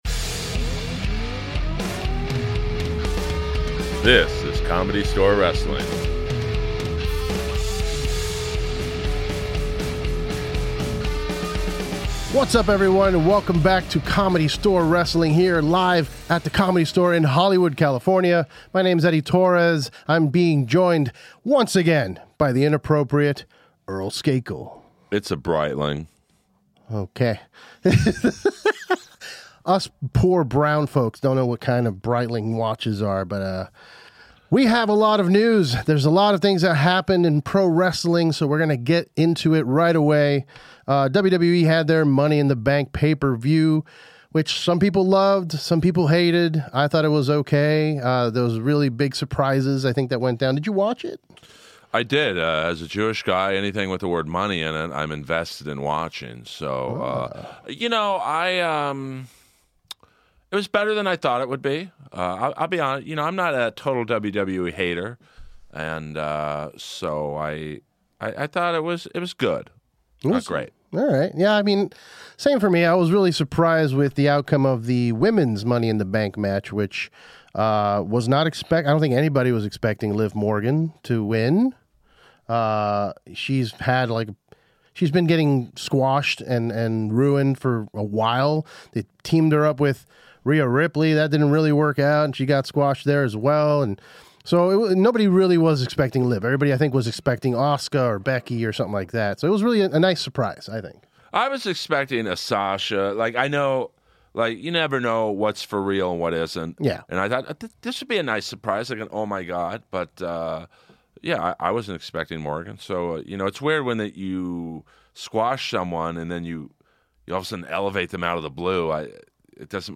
We're back with another episode of CSW with special guest, former ECW and current XPR wrestler Jasmin St. Claire is in to talk about... stuff.